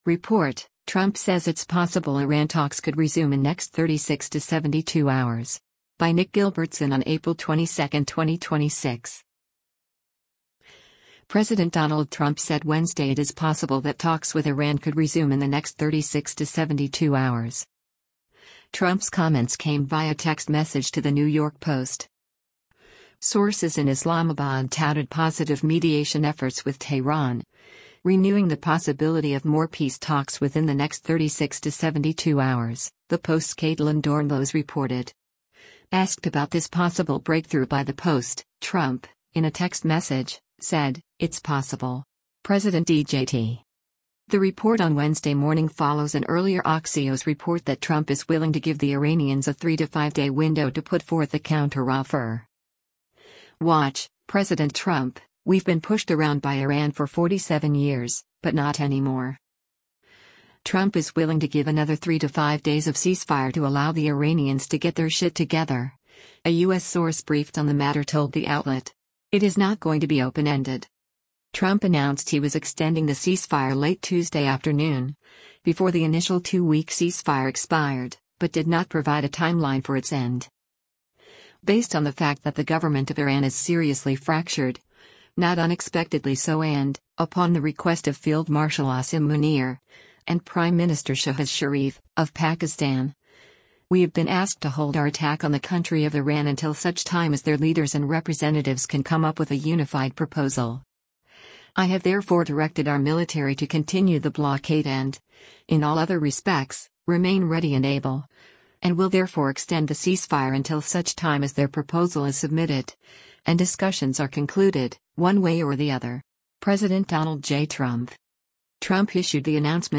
WATCH — President Trump: “We’ve Been Pushed Around by Iran for 47 Years, but Not Anymore”: